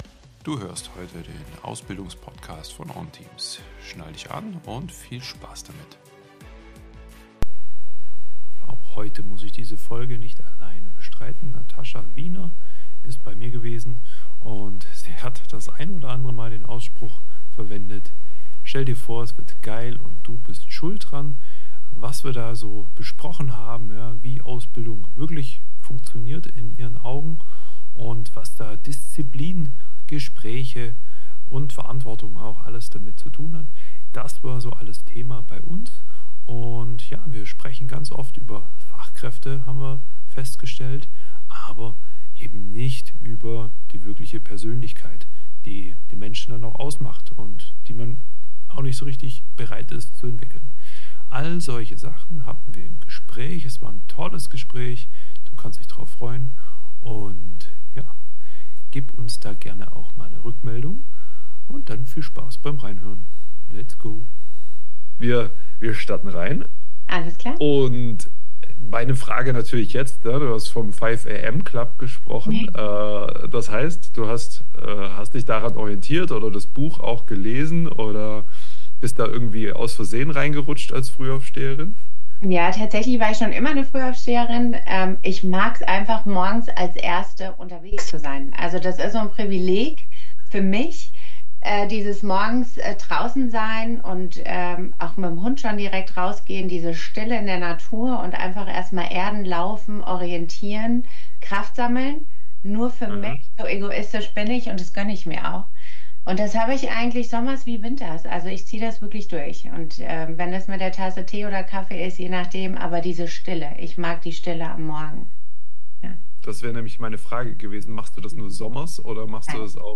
Wir sprechen über die größten blinden Flecken in der Ausbildung, über ein System, das Verhalten trainiert statt Entwicklung ermöglicht, und über die Frage, was junge Menschen wirklich brauchen, um im Leben und im Job klarzukommen. Ein ehrliches Gespräch über Anspruch und Realität – und darüber, warum echte Entwicklung immer bei uns selbst beginnt.